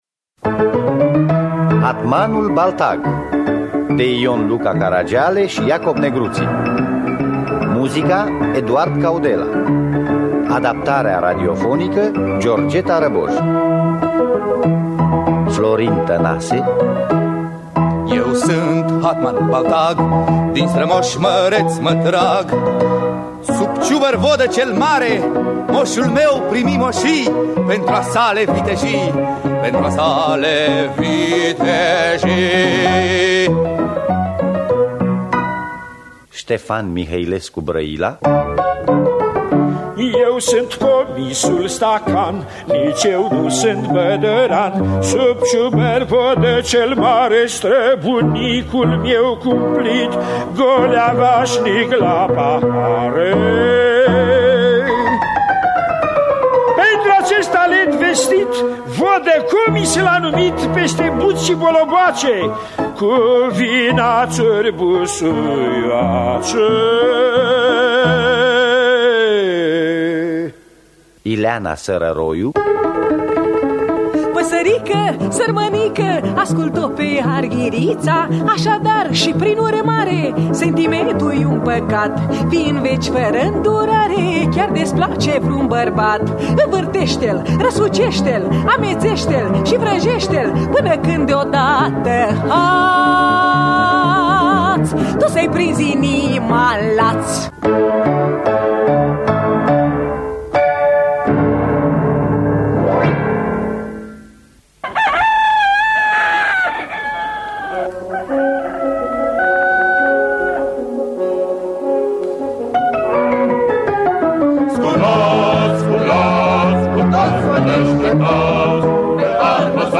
Adaptarea radiofonică
Înregistrare din anul 1979 (5 februarie).